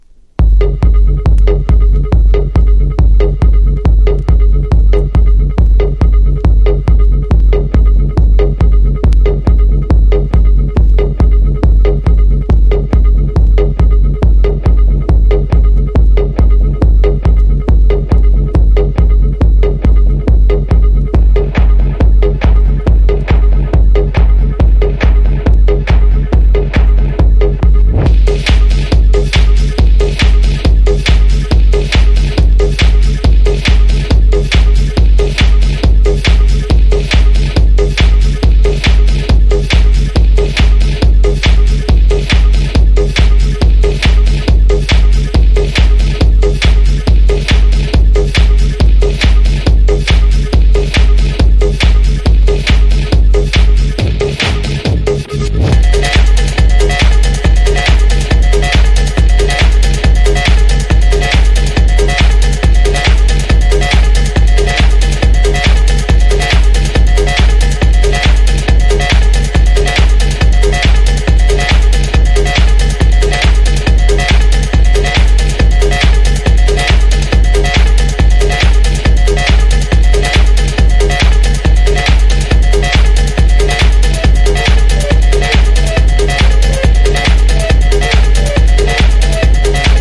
ELECTRO HOUSE / TECH HOUSE# TECHNO / DETROIT / CHICAGO